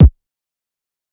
KICK 1.wav